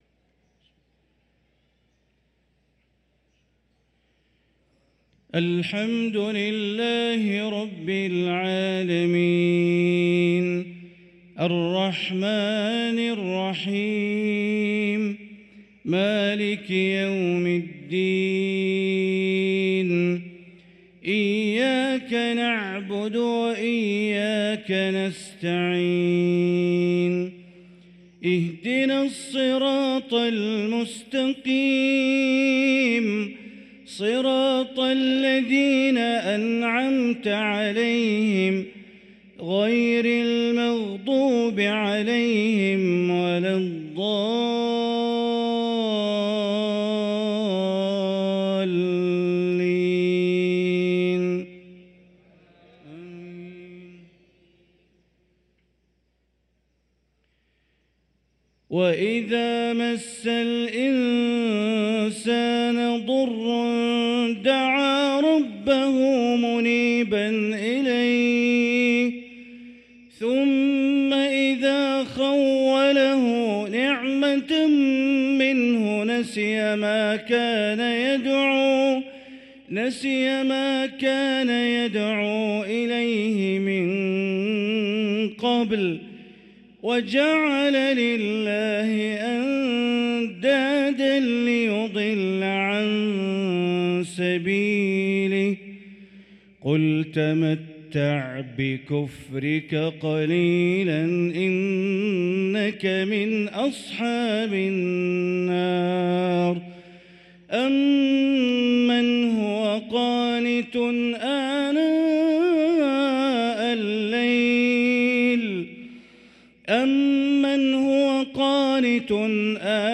صلاة العشاء للقارئ بندر بليلة 25 جمادي الآخر 1445 هـ
تِلَاوَات الْحَرَمَيْن .